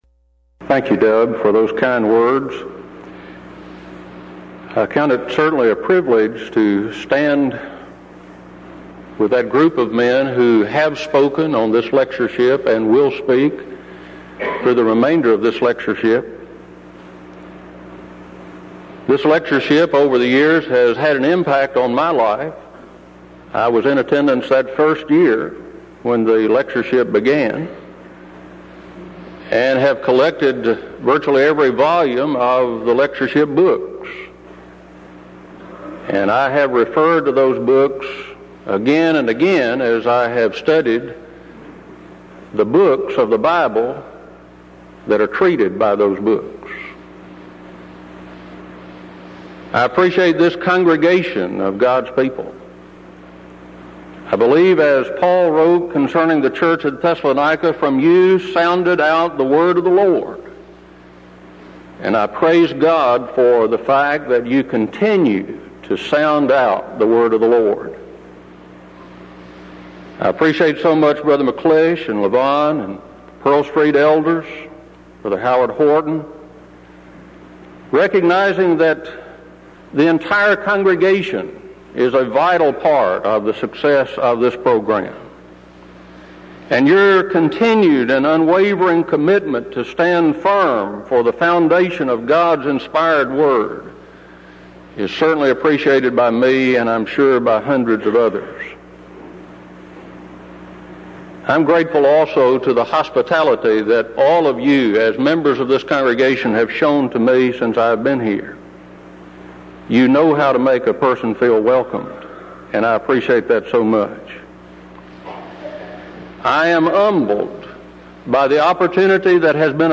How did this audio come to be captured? Event: 1992 Denton Lectures Theme/Title: Studies In Ezra, Nehemiah And Esther